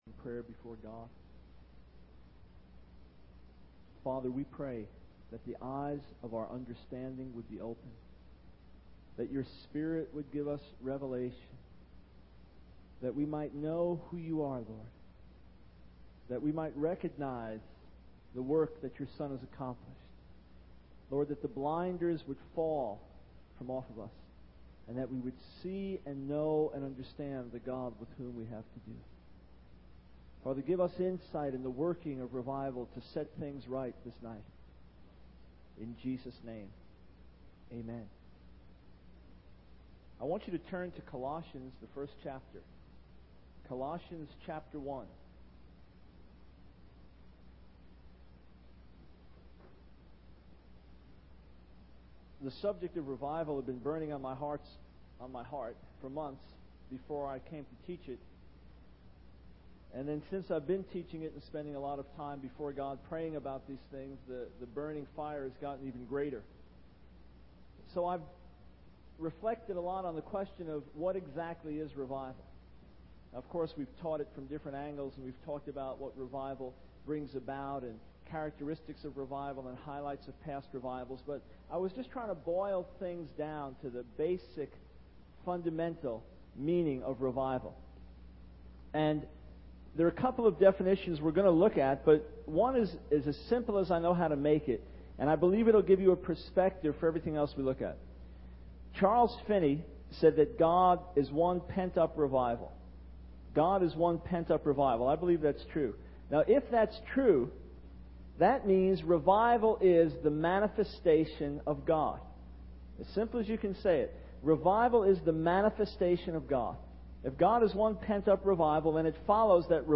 In this sermon, the preacher discusses the experience of revival and the overwhelming joy and love for Jesus that comes with it. He emphasizes that God's blessing is on the preaching of the cross, which is the central message that the world must hear.